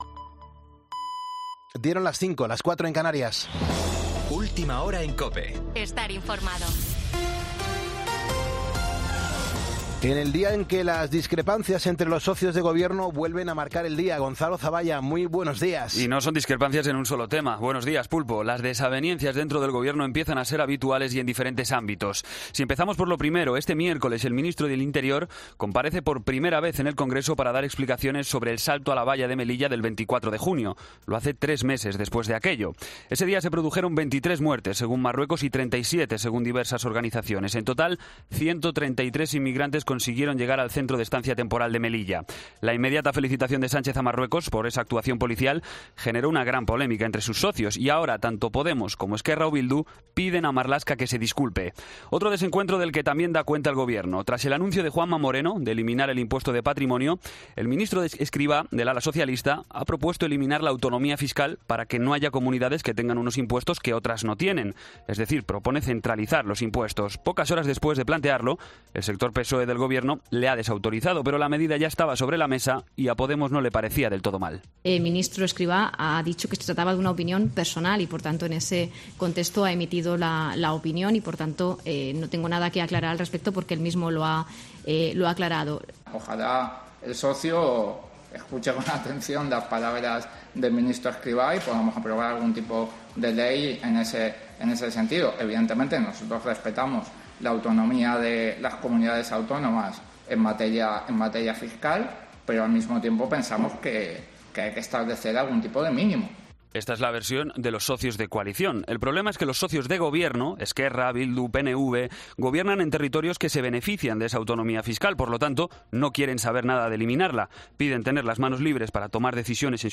Boletín de noticias COPE del 21 de septiembre a las 05:00 hora